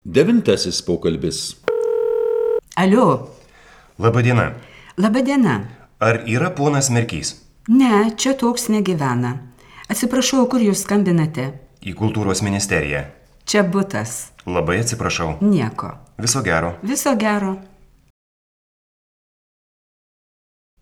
02_Dialog_09.wav